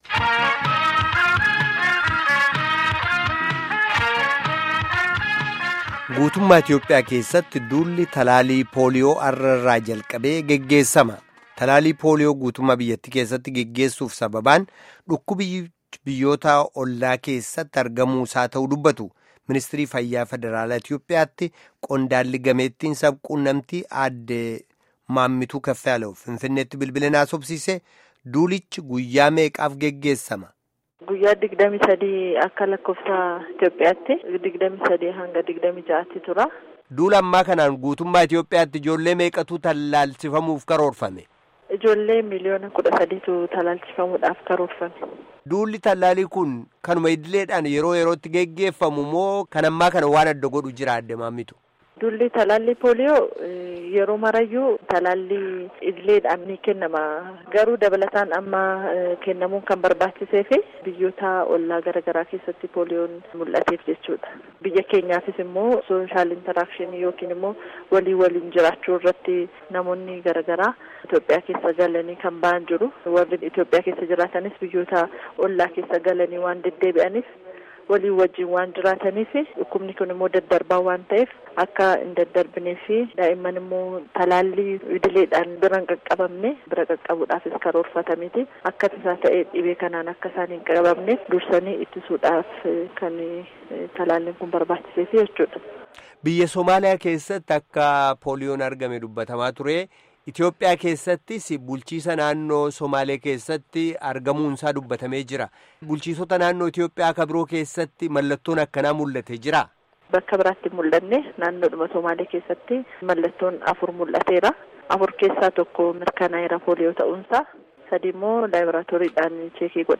Gaaffii fi deebii Armaa Gaditti Caqasaa